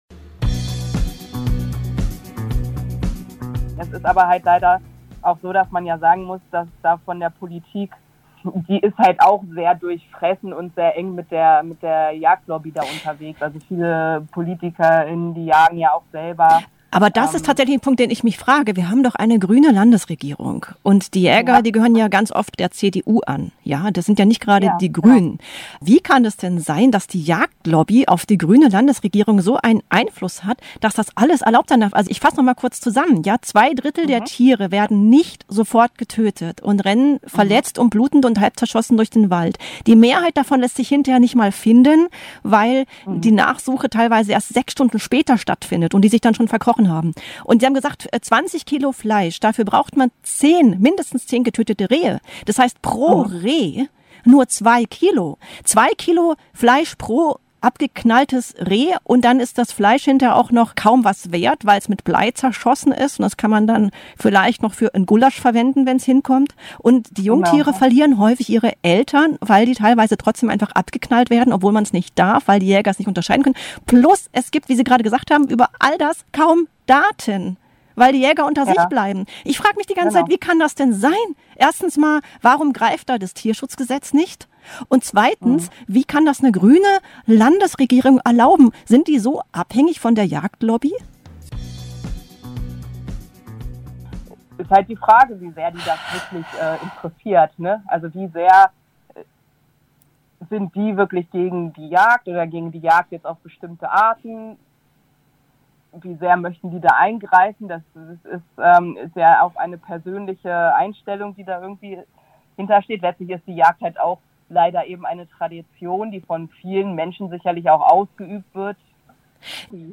Zusammenfassung